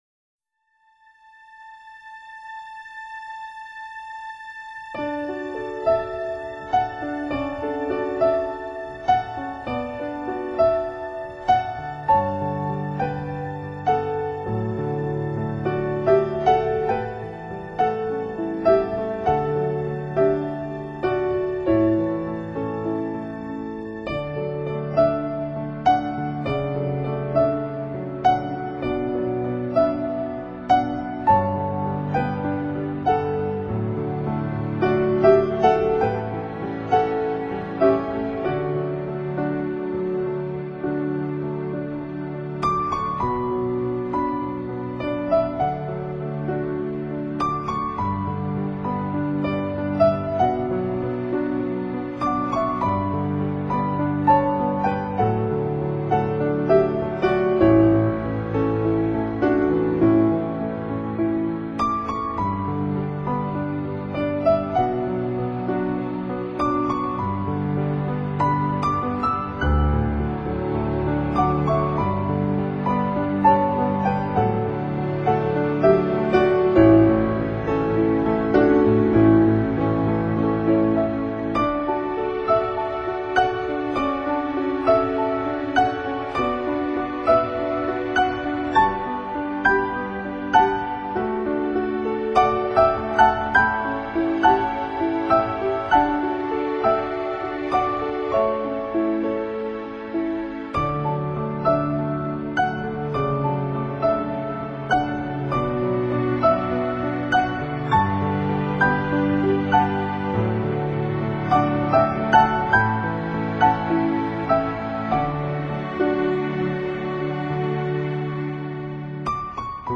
（云淡风轻/钢琴） 激动社区，陪你一起慢慢变老！